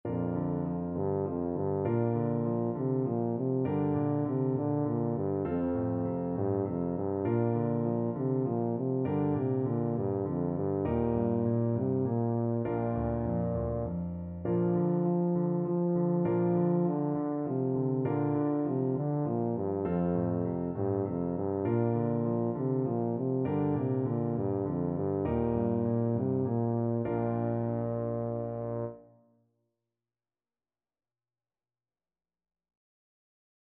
Traditional Trad. Arirang (traditional Korean) Tuba version
Tuba
"Arirang" is a Korean folk song, often considered as the unofficial national anthem of Korea.
Bb major (Sounding Pitch) (View more Bb major Music for Tuba )
3/4 (View more 3/4 Music)
F3-F4